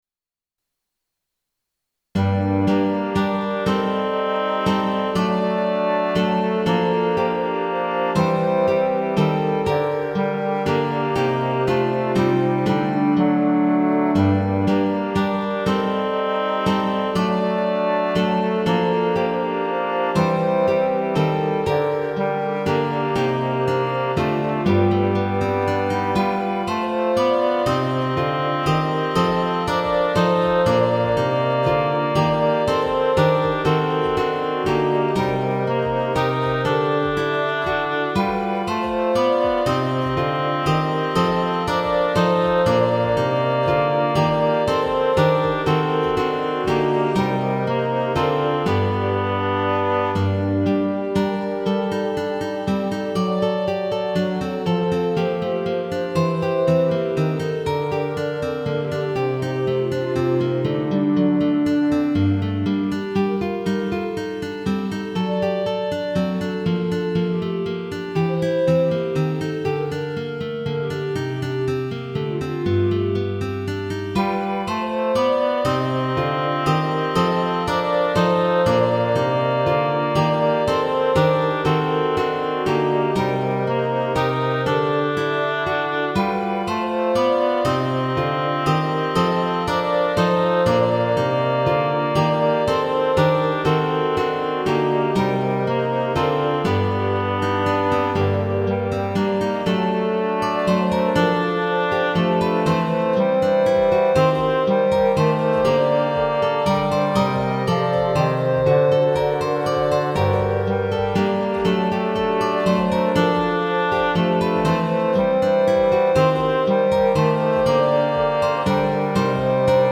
▼DL↓   1.0 リコーダー フルート オーボエ ファゴット ギター